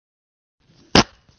真实的屁 " 屁9
描述：真屁
Tag: 现实 放屁 真正